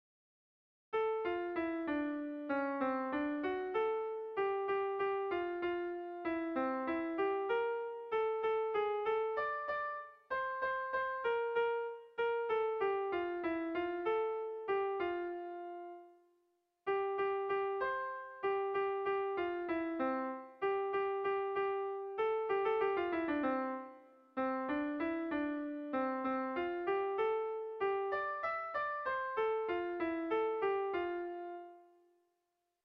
Erlijiozkoa
Zortziko handia (hg) / Lau puntuko handia (ip)
ABDE